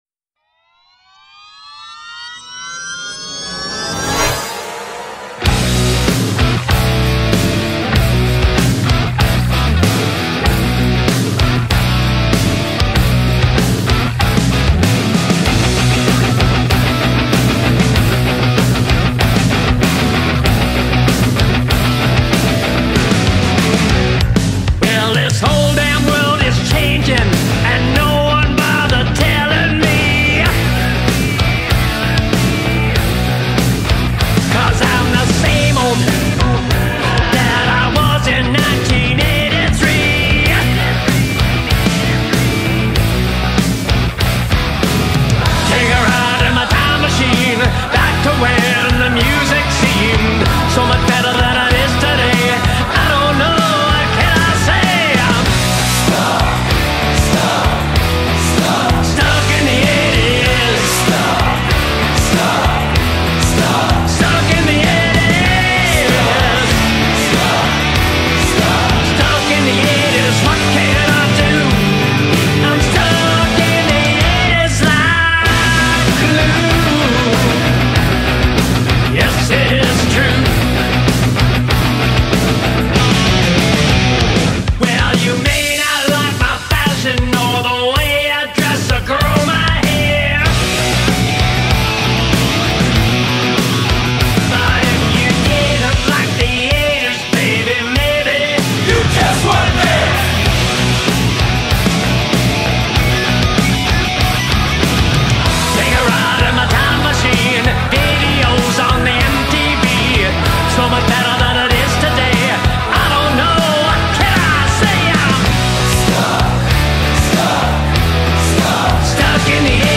The riffs are bold, the chorus ready made for shouting.